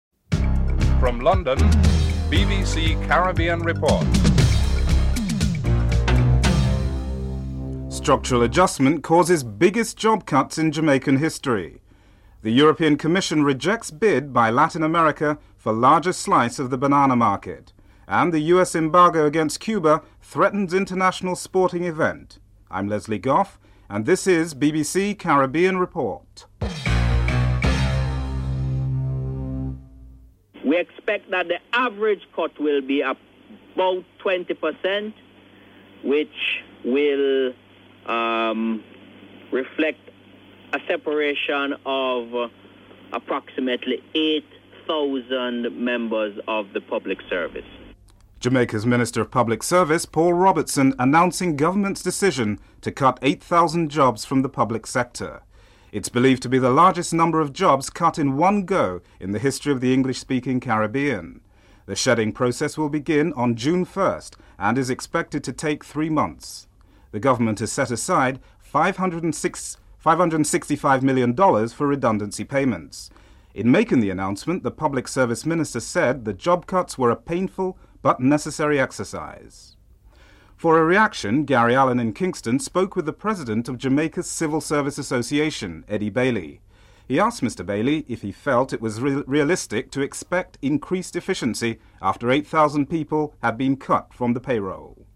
1. Headlines (00:00-00:30)
2. Jamaica’s Minister of Public Service, Paul Robertson announces a twenty percent cut in public sector jobs, the largest in the history of the English-speaking Caribbean (00:31-01:29)